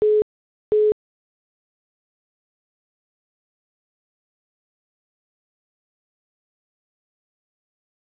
callwaiting_se.wav